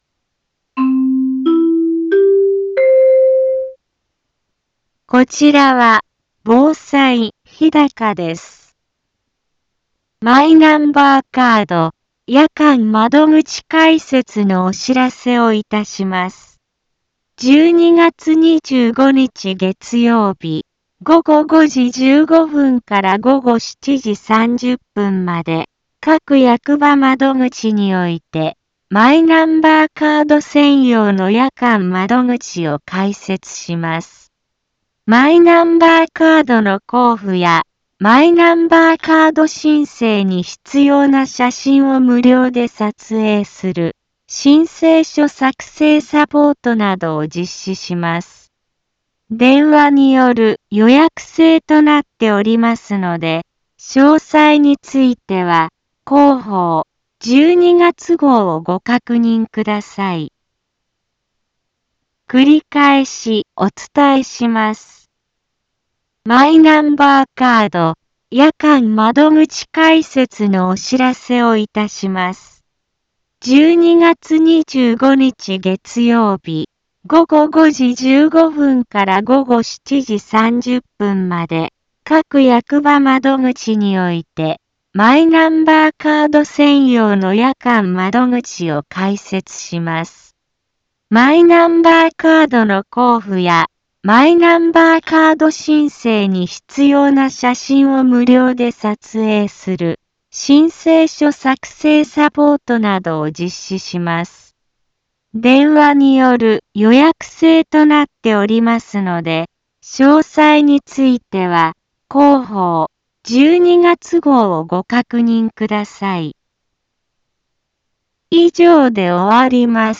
Back Home 一般放送情報 音声放送 再生 一般放送情報 登録日時：2023-12-18 15:04:35 タイトル：マイナンバーカード夜間窓口開設のお知らせ インフォメーション： マイナンバーカード夜間窓口開設のお知らせをいたします。 12月25日月曜日、午後5時15分から午後7時30分まで、各役場窓口において、マイナンバーカード専用の夜間窓口を開設します。